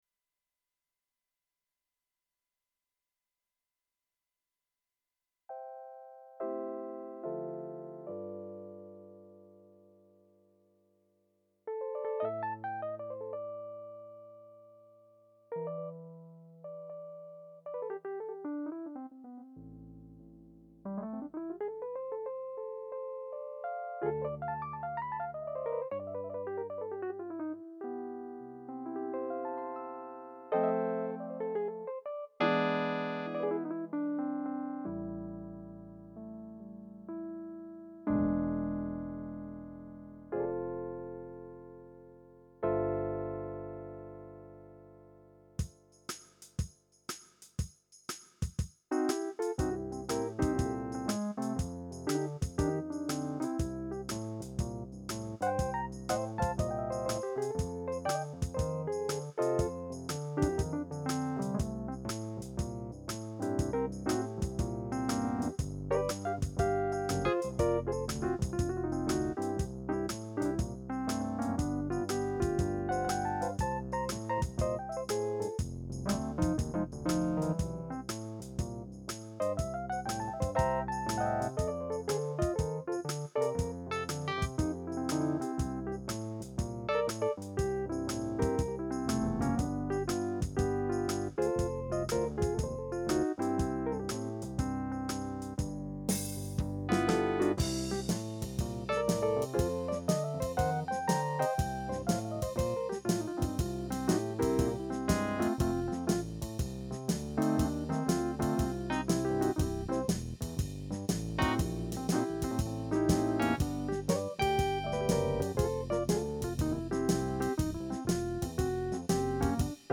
I decided to hit record on my H4N while playing.
The smokey sound of the EP in the blues clip is amazing.